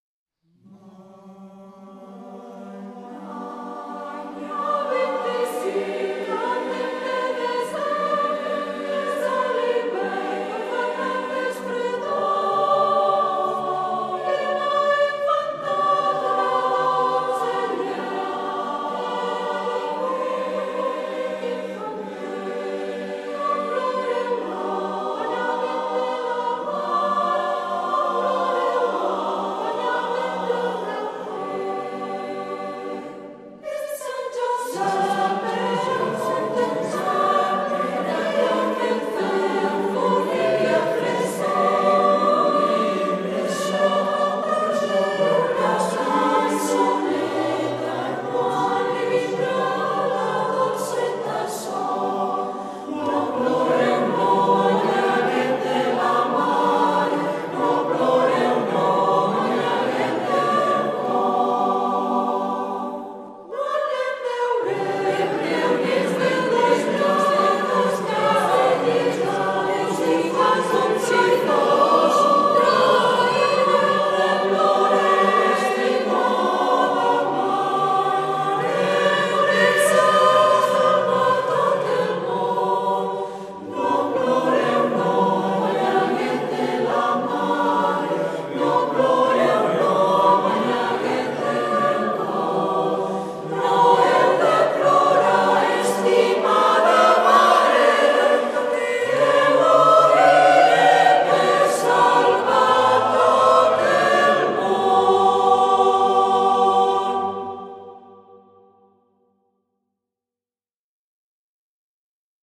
Fa# M
Fa# 3 - Re 4